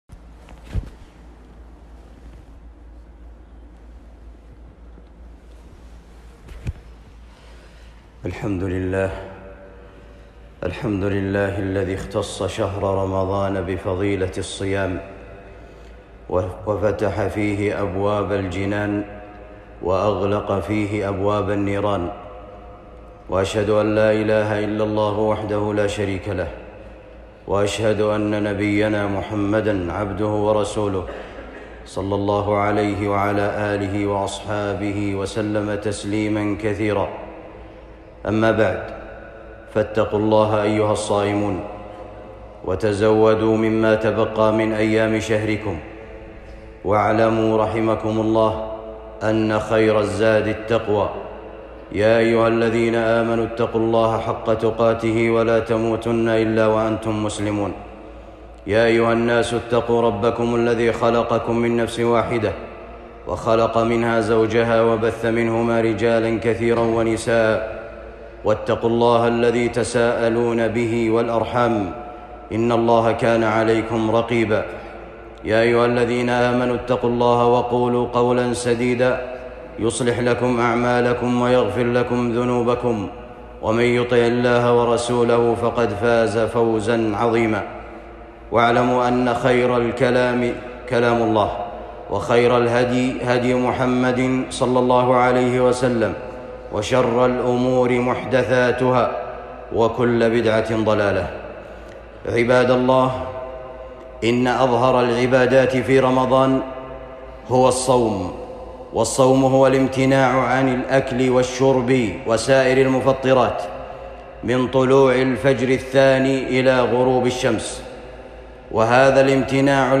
خطبة بعنوان حقيقة صيام رمضان